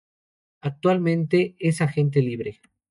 Pronounced as (IPA) /ˈlibɾe/